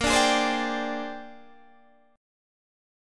A#13 chord